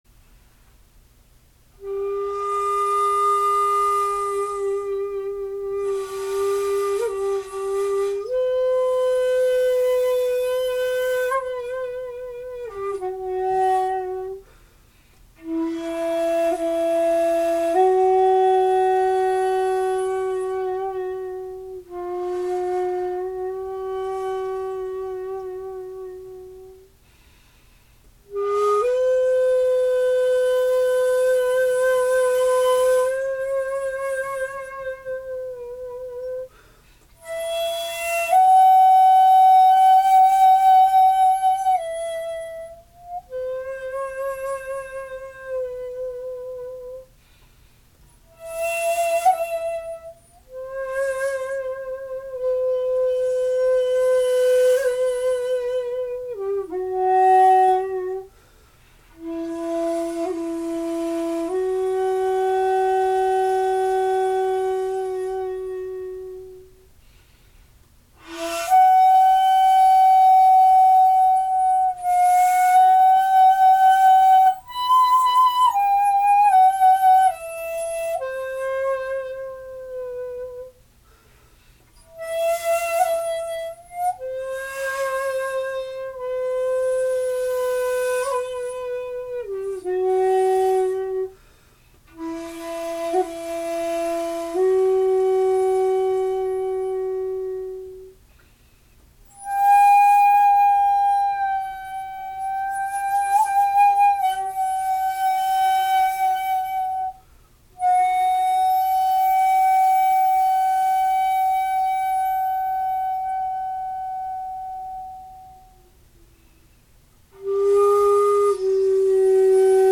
ピッチピッチ（音程）と喧しく叫ばれる前の時代ですので現代管よりは音が低めですが、その分、落ち着いた響きです。
録音はしていますが、所詮機械ですので生の音にはほど遠いことをご留意ください。
－＞優しい中にもしっかりした響きで、ほんとうに吹いていて気持ちの良い竹です。